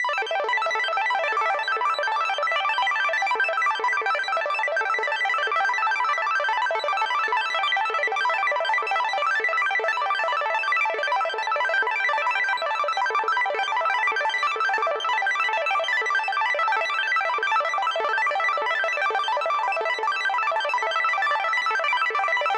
Arplike - A9sus4 in 2 octaves .mid